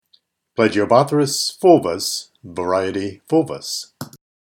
Pronunciation/Pronunciación:
Pla-gi-o-bó-thrys fúl-vus var. fúl-vus